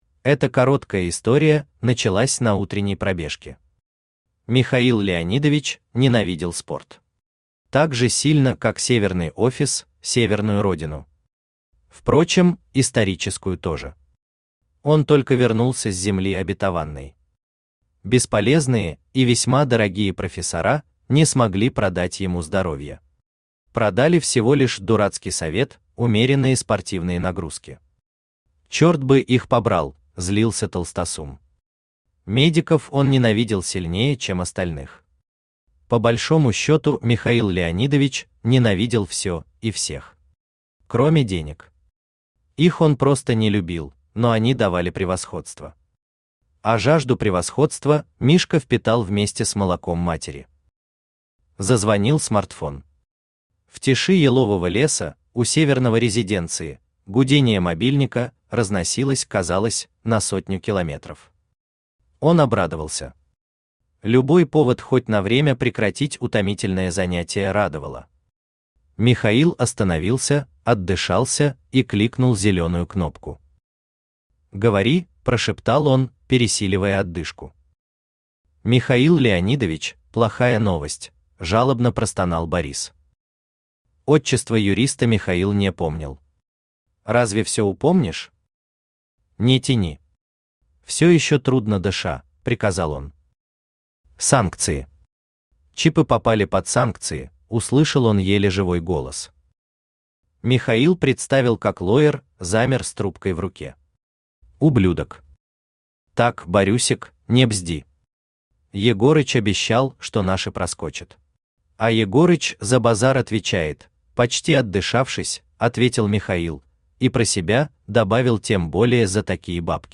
Аудиокнига Сам | Библиотека аудиокниг
Aудиокнига Сам Автор ШаМаШ БраМиН Читает аудиокнигу Авточтец ЛитРес.